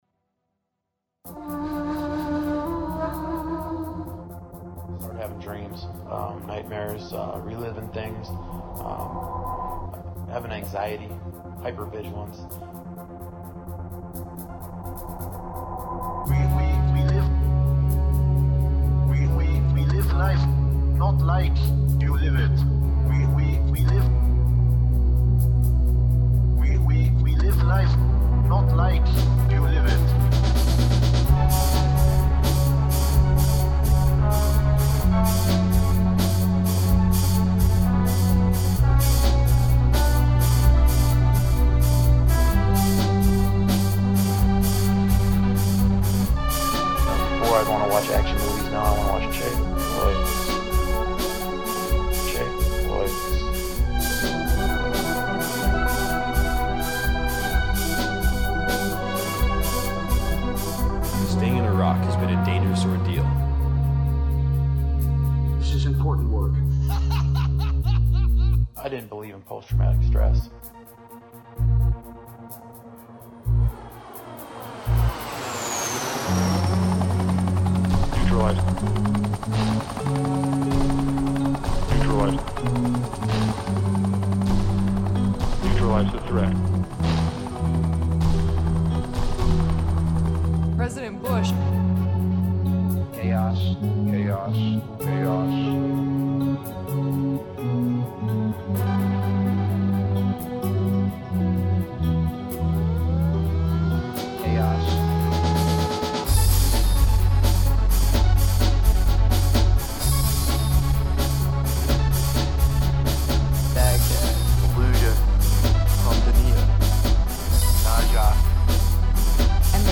Drum & bass
Ambient
Trance